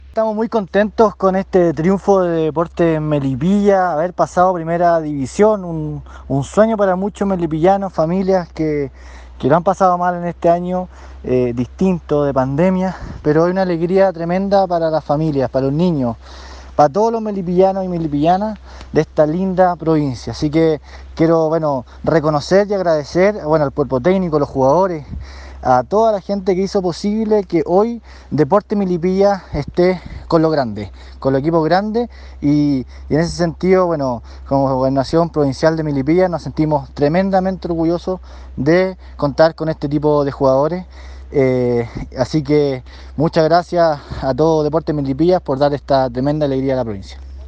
Por su parte, autoridades y candidatos para las elecciones de abril próximo quisieron entregar su saludo al programa radial Entre Portales:
Gobernador(s) Provincial de Melipilla, José Pedro Guilisasti